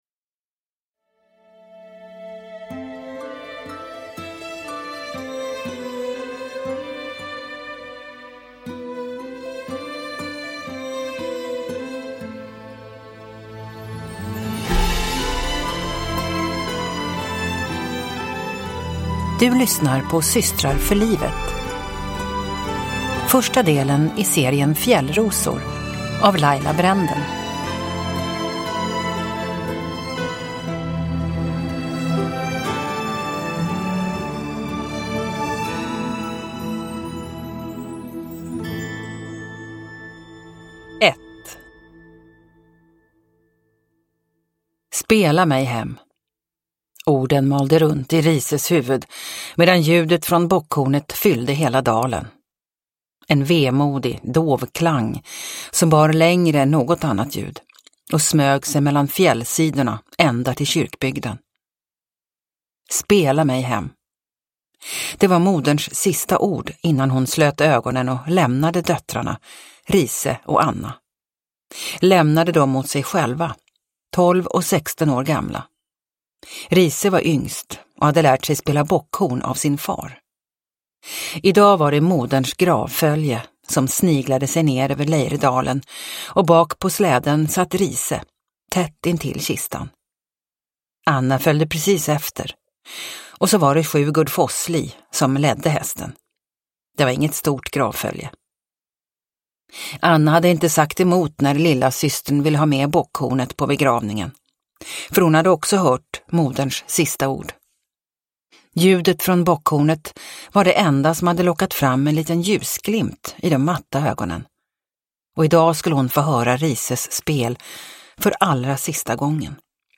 Systrar för livet – Ljudbok – Laddas ner